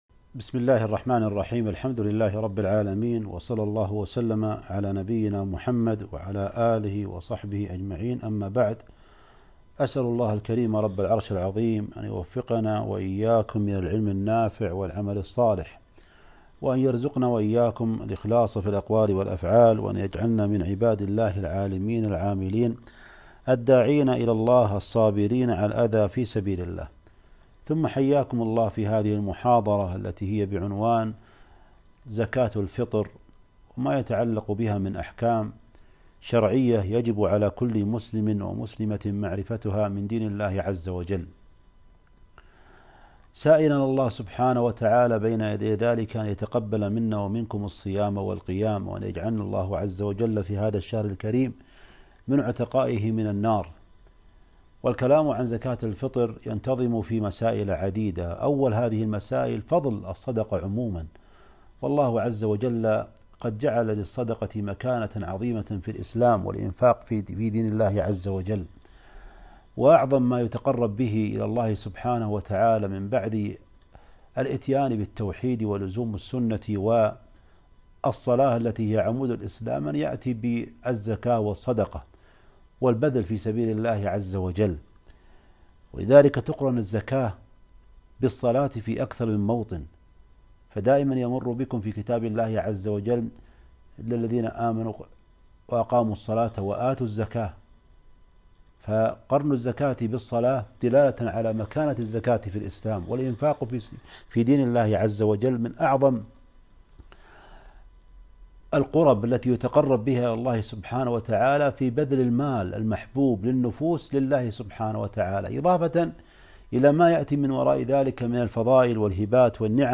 كلمة - أحكام زكاة الفطر